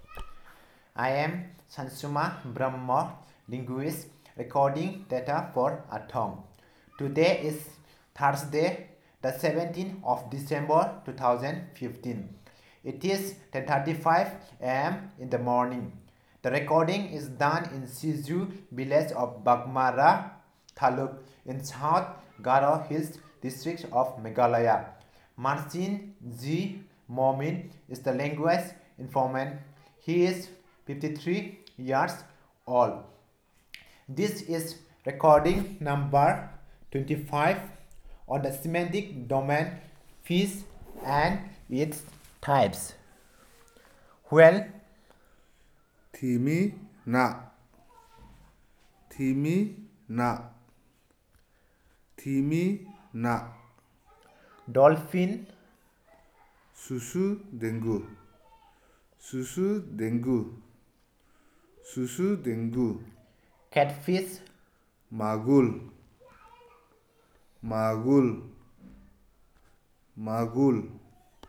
Elicitation of words about fish and related